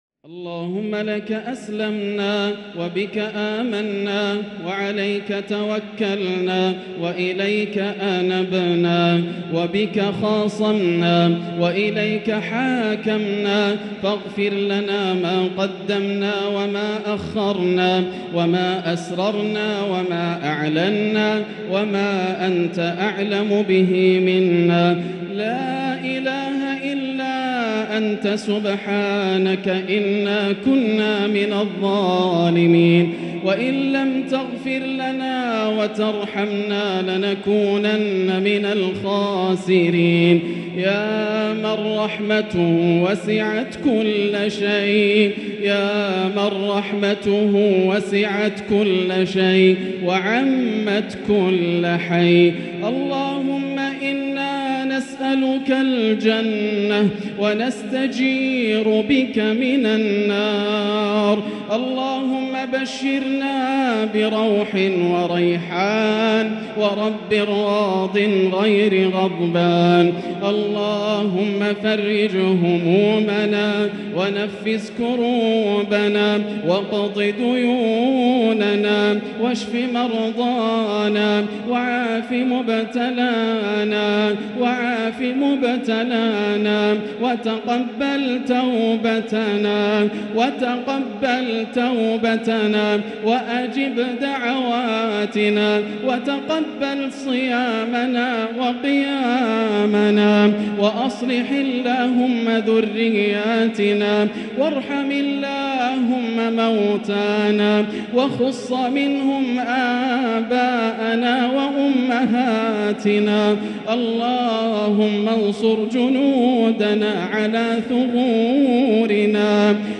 دعاء القنوت ليلة 6 رمضان 1444هـ | Dua 6 st night Ramadan 1444H > تراويح الحرم المكي عام 1444 🕋 > التراويح - تلاوات الحرمين